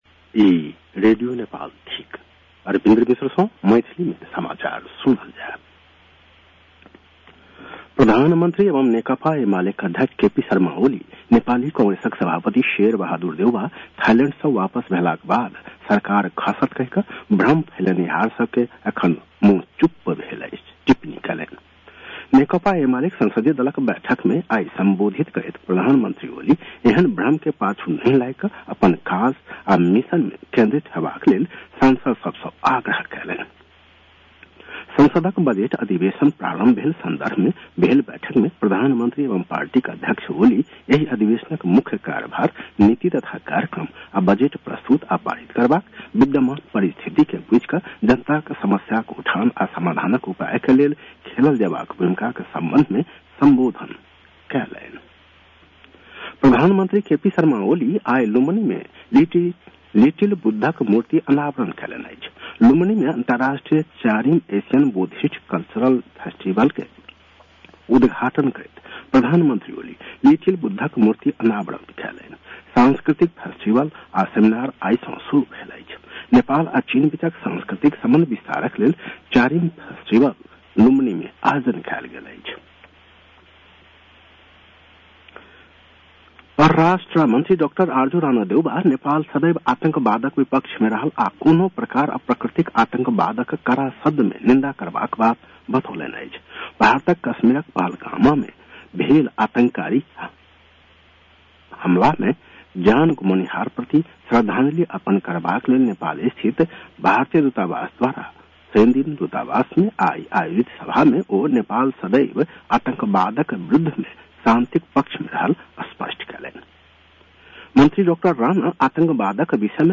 मैथिली भाषामा समाचार : १३ वैशाख , २०८२